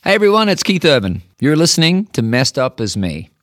LINER Keith Urban (Messed Up As Me) 5